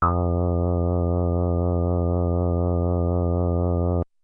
Roland JX8 P Sensitive Sync " Roland JX8 P Sensitive Sync E4 (65 F3IICX)
标签： MIDI-速度-12 ESharp4 MIDI音符-65 罗兰-JX-8P 合成器 单票据 多重采样
声道立体声